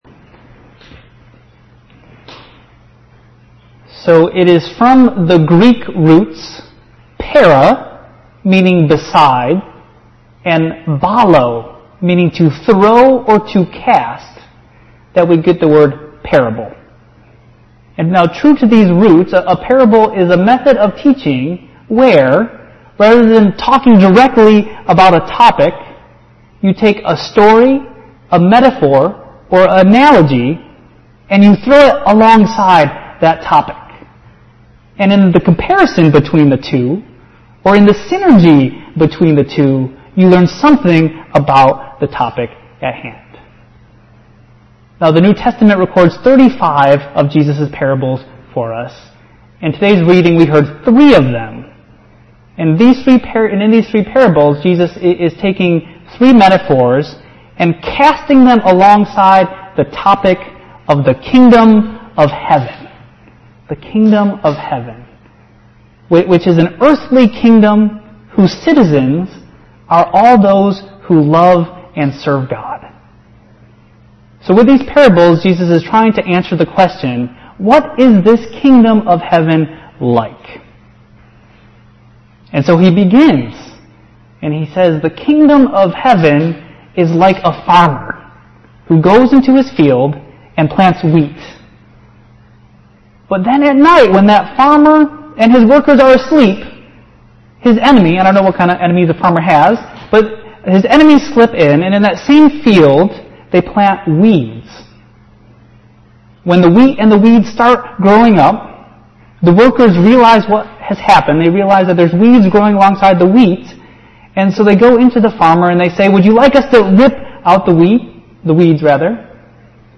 The doxology is a favorite hymn sung by Christians throughout the world. Here are alternative versions of it and the amazing story behind it.
pianist